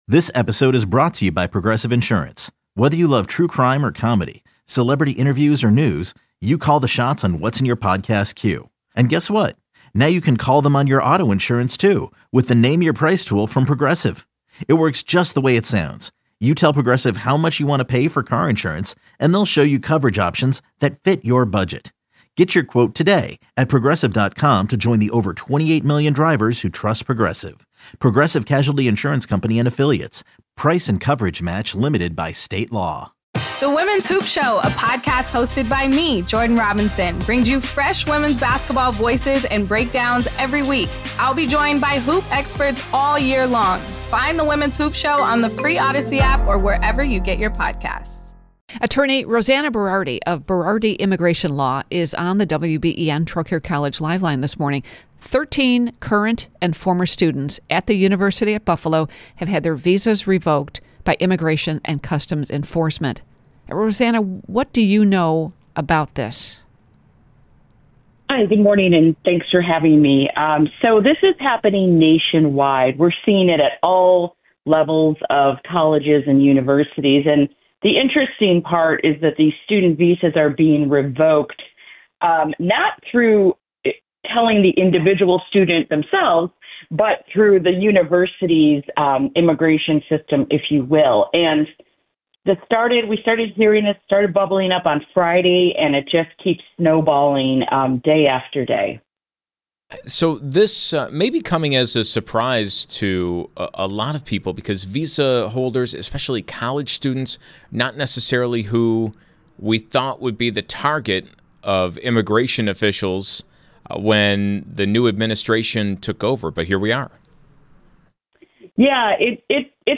Collection of LIVE interviews from Buffalo's Early News on WBEN